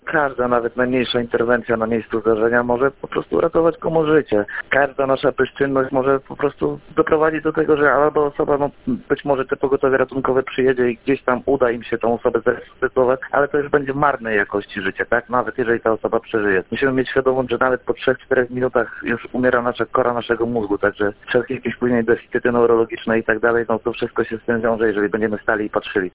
– W krytycznych sytuacjach liczy się dosłownie każda minuta – mówi radny Marcin Jakowicz, który jest też ratownikiem medycznym.